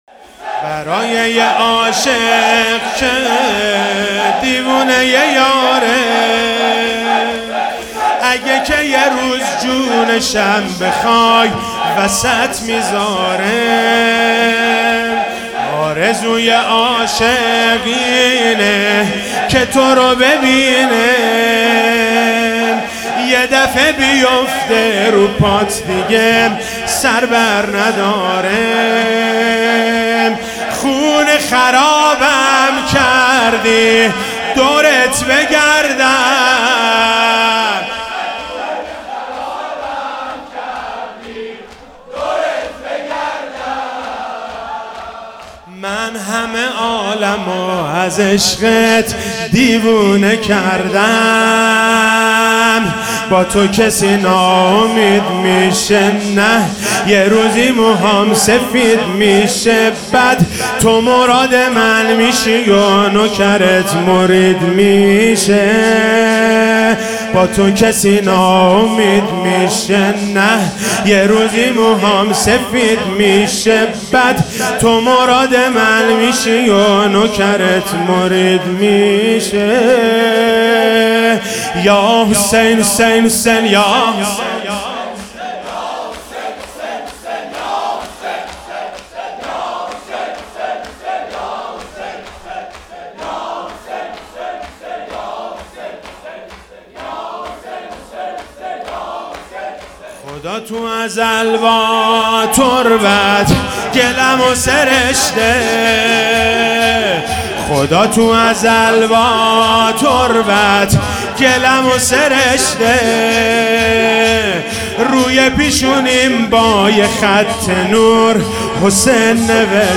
محرم99 - شب پنجم - شور - برای یه عاشق که دیوونه یاره